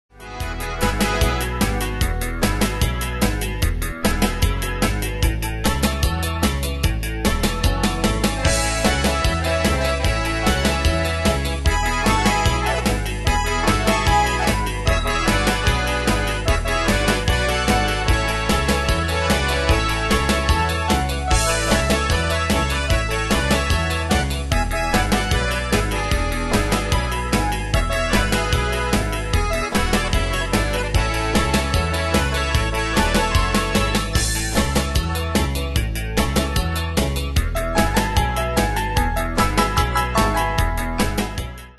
Style: Country Année/Year: 1995 Tempo: 149 Durée/Time: 4.07
Danse/Dance: Country Cat Id.
Pro Backing Tracks